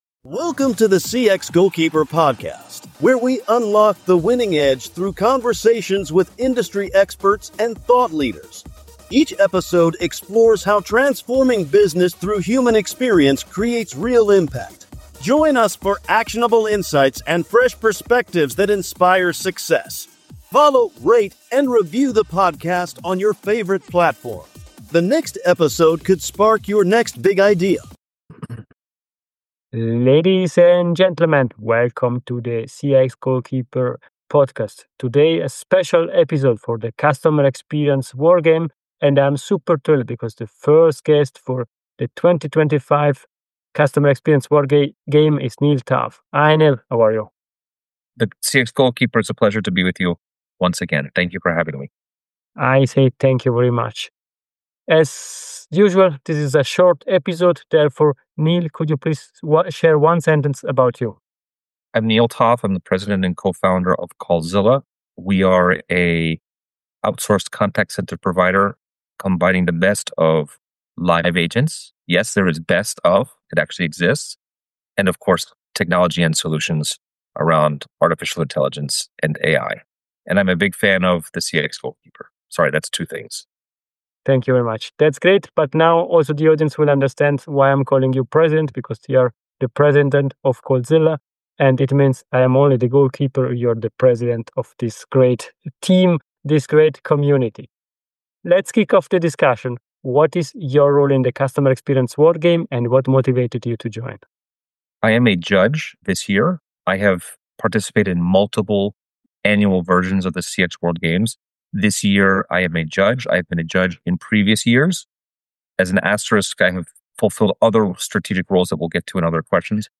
These are brief interviews that share the experiences of outstanding individuals who help charities improve.